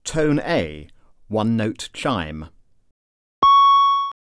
Alert Tone: A